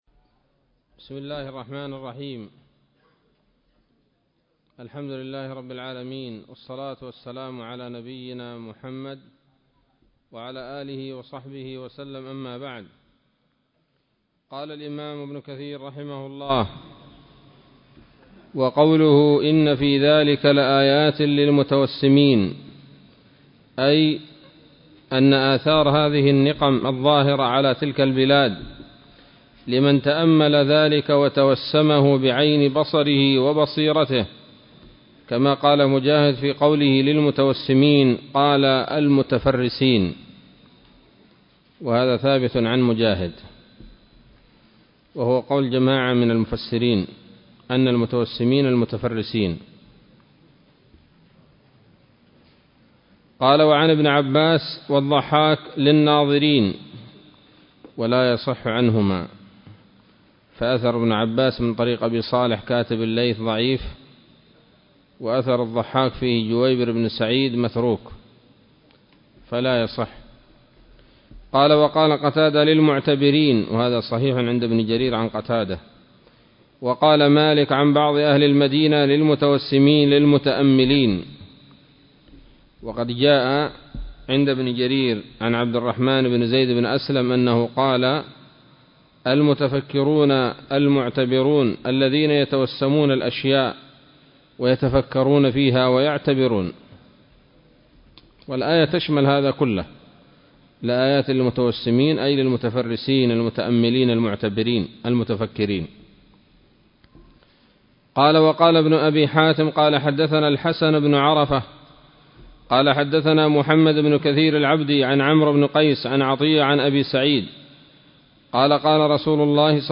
الدرس الثامن من سورة الحجر من تفسير ابن كثير رحمه الله تعالى